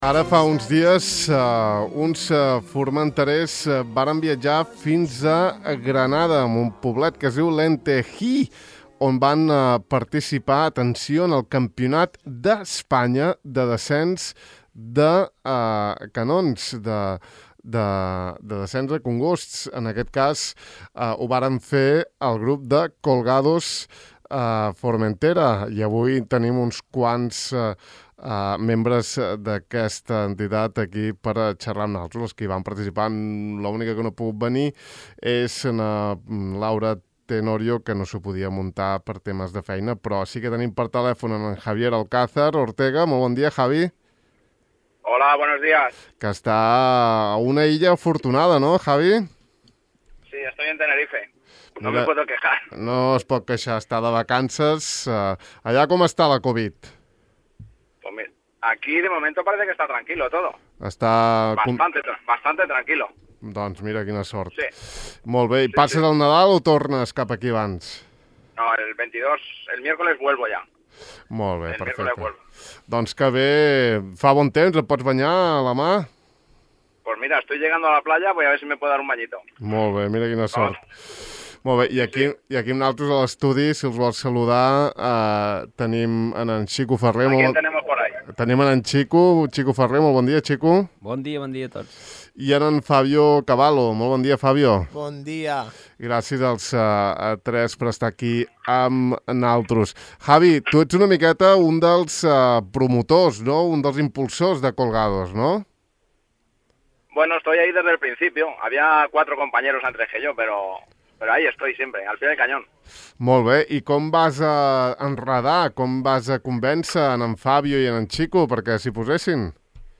Ahir van passar pels micròfons de Ràdio Illa, on vam poder escoltar l’experiència i saber més de les seves aventures aquí a Formentera.